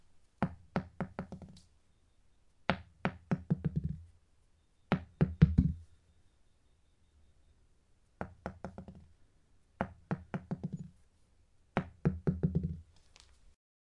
槌球
描述：一个槌球在地板上滚动，撞到墙上，然后滚回来
标签： 门球 门球
声道立体声